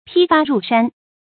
披发入山 pī fā rù shān 成语解释 谓离开俗世而隐居。